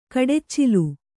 ♪ kaḍeccilu